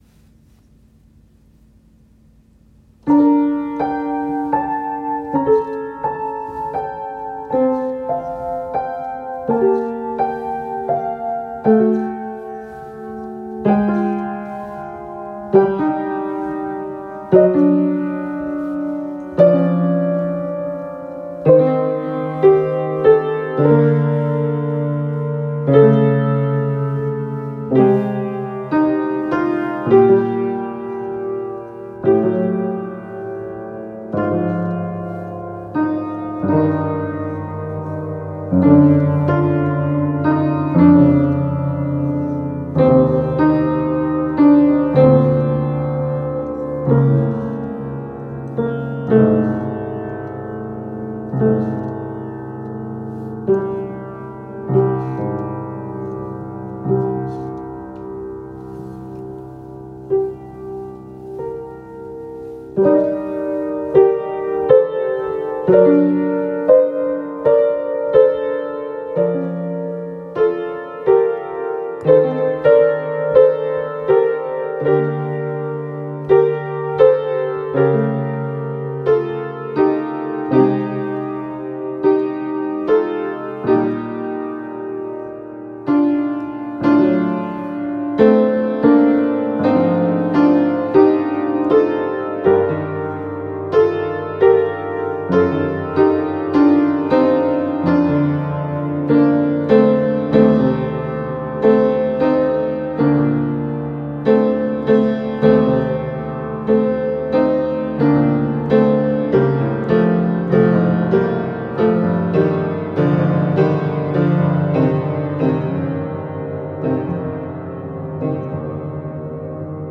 improvisation for piano